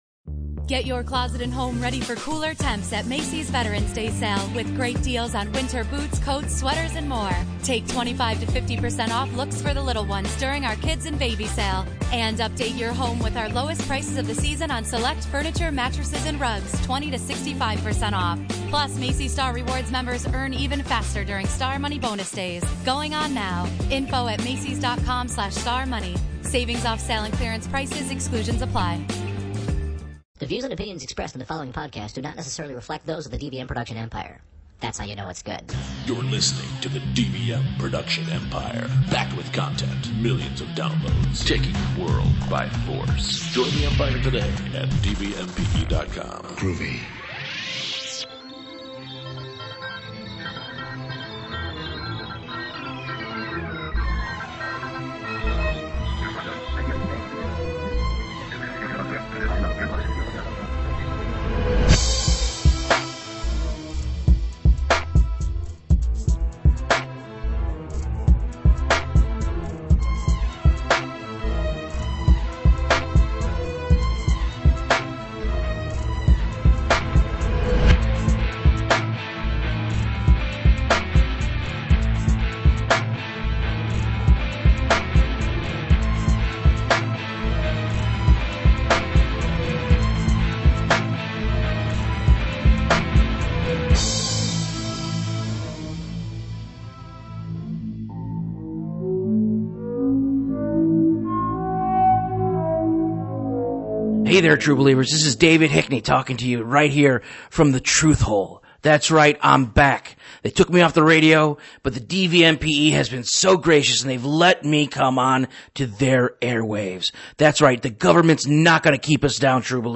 Welcome True Believers, this week on the very first episode of Freak of the Week, we discuss the very real, very scary phenomenon known as a potty mouth. Listen as we talk to experts and take calls from witnesses of these ghosts of child gamers.